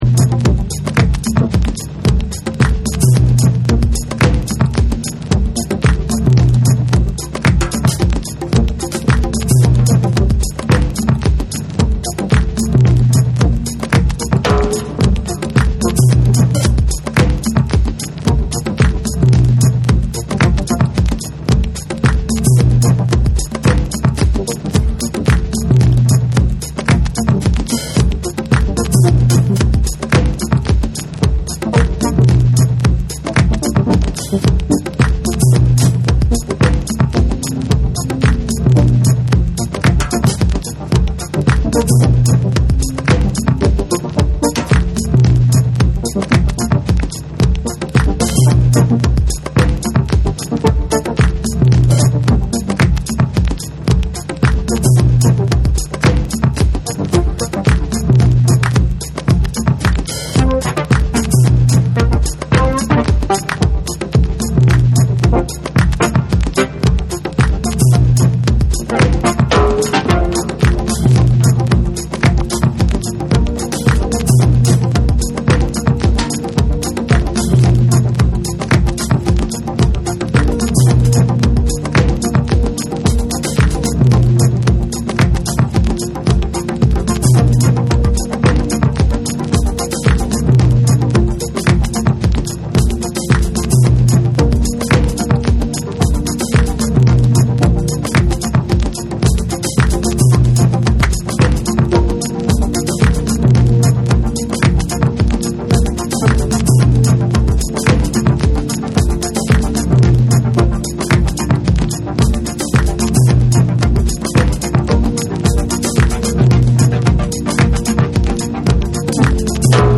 B面にはインストを収録。
TECHNO & HOUSE / ORGANIC GROOVE